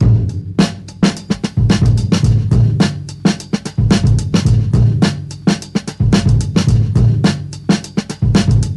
• 109 Bpm Drum Groove A Key.wav
Free drum groove - kick tuned to the A note. Loudest frequency: 645Hz
109-bpm-drum-groove-a-key-kCe.wav